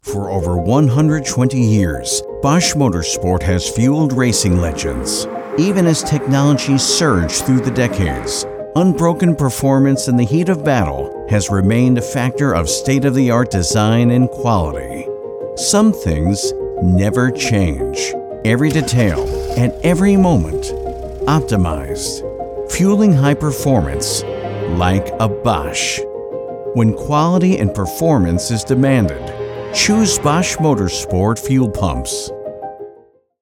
Automotivo
My style of VO is bright, clear and articulate.
My voice can be described as clear, friendly, personable, confident, articulate, and smooth.
Sennheiser 416 mic